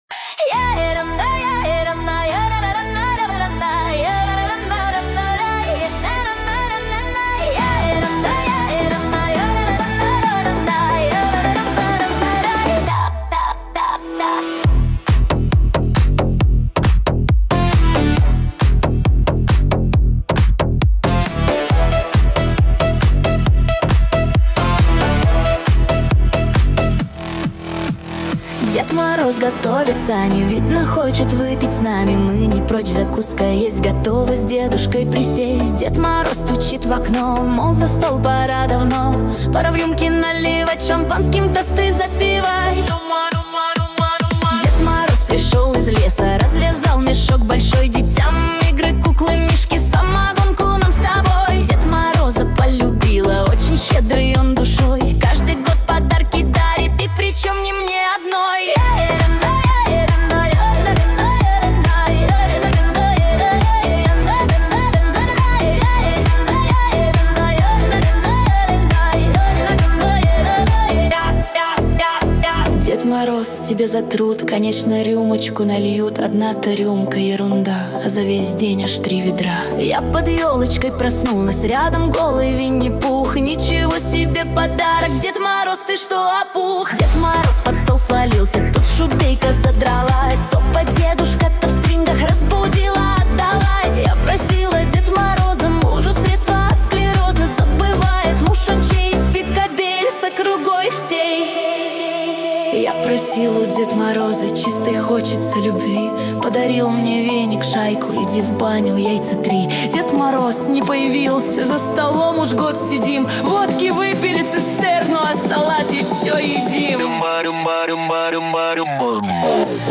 13 декабрь 2025 Русская AI музыка 73 прослушиваний